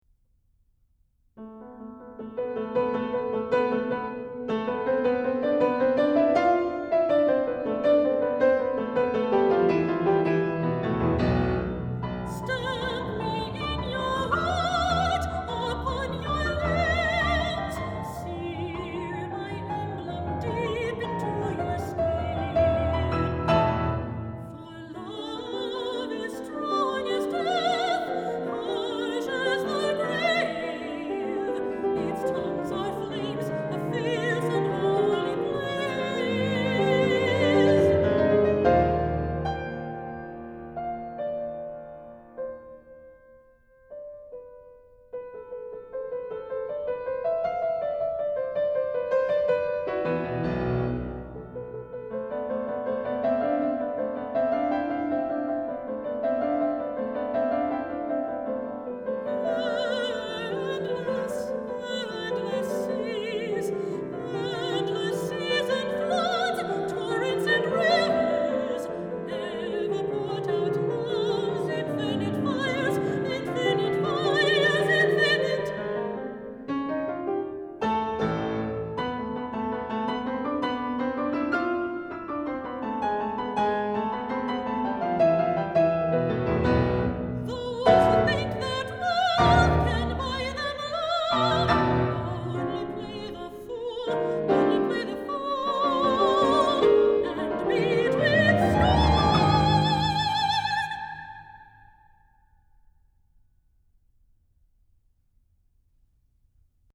Medium, piano (c. 6:30)